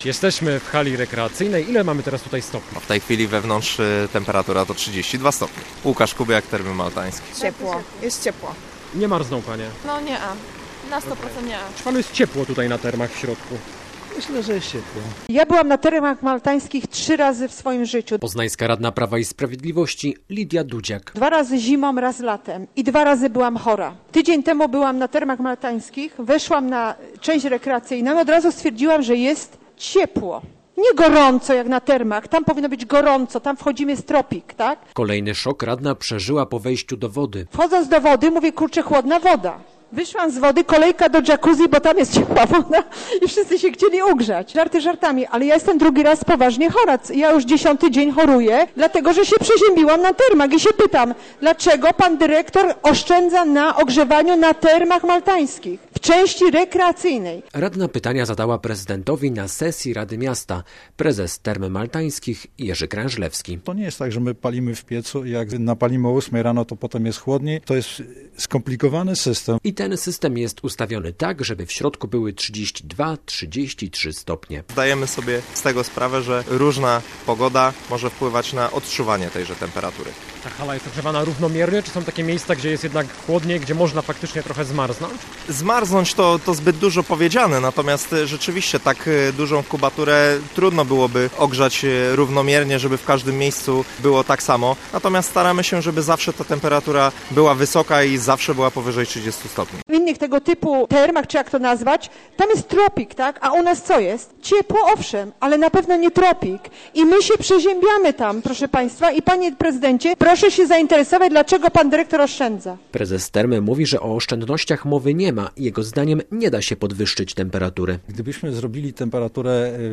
Bez emocji do aquaparku pojechał nasz reporter, by sprawdzić czy rzeczywiście w Termach jest za chłodno.